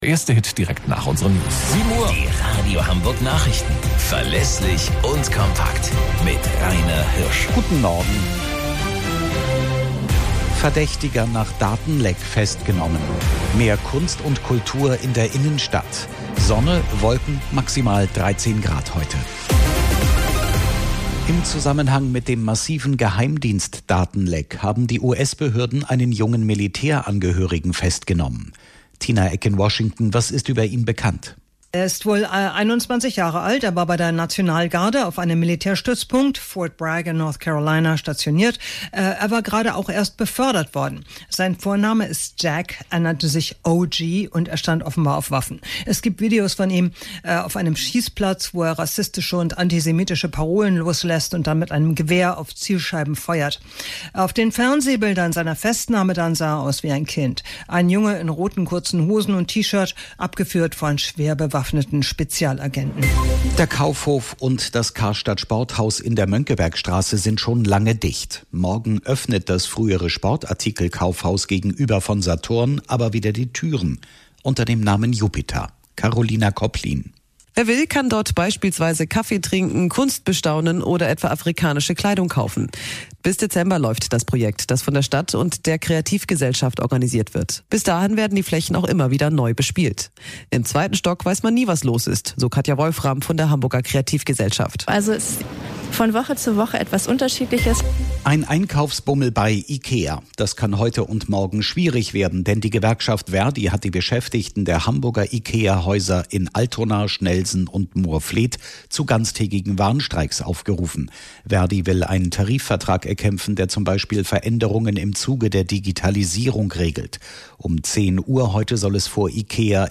Radio Hamburg Nachrichten vom 14.06.2022 um 03 Uhr - 14.06.2022